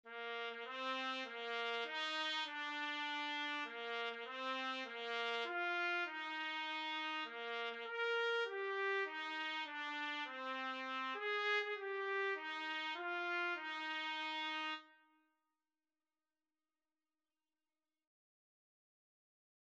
3/4 (View more 3/4 Music)
Bb4-Bb5
Beginners Level: Recommended for Beginners